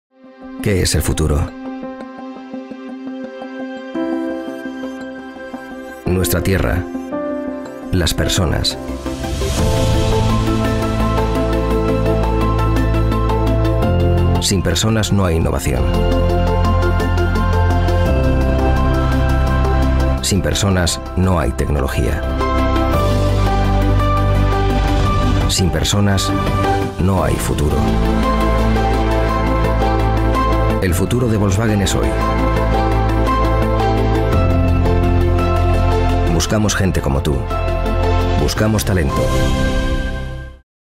Commercieel, Natuurlijk, Veelzijdig, Vertrouwd, Zakelijk
Corporate